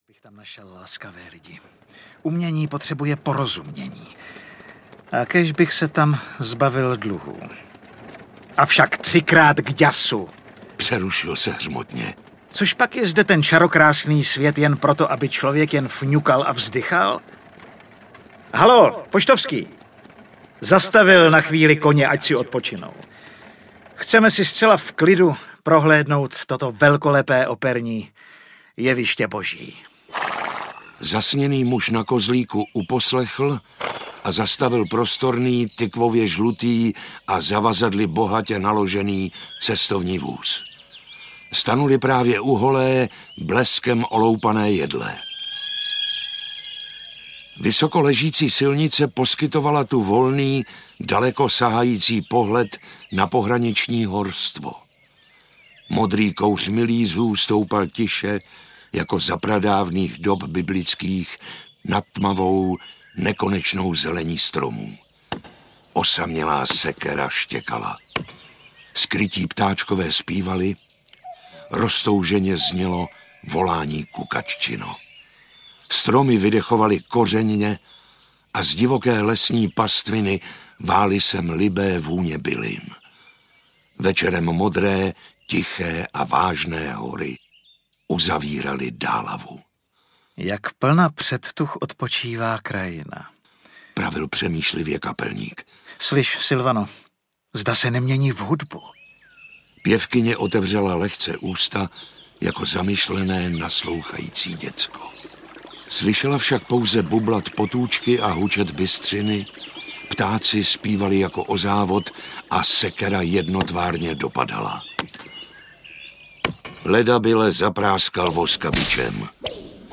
Weberova romantická cesta audiokniha
Ukázka z knihy